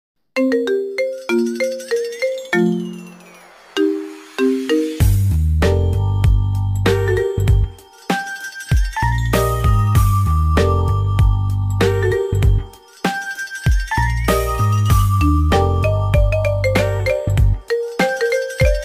with a sleek, modern composition